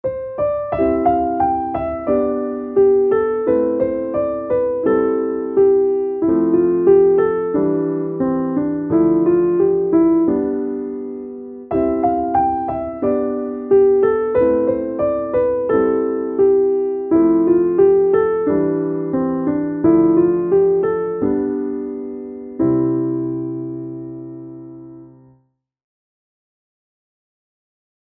今日の作曲屋さんは優しいピアノソロです🍀